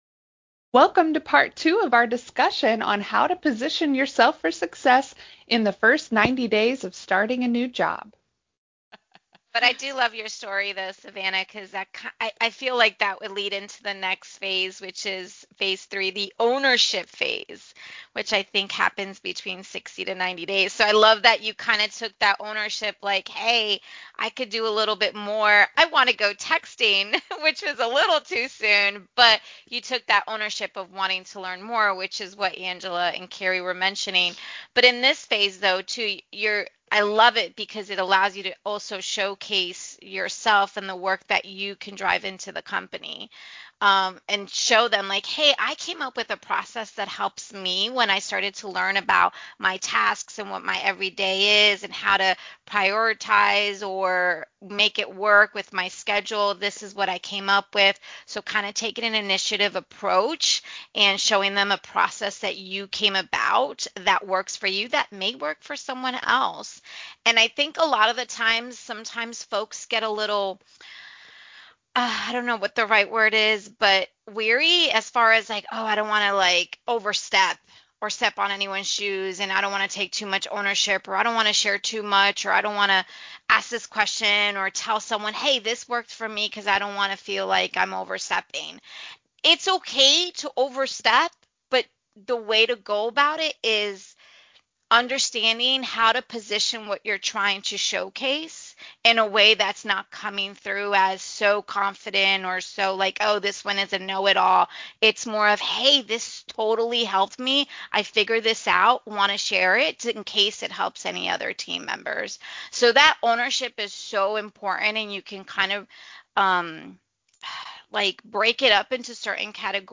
Roundtable: New Job? What Happens After You Prove You Belong - craresources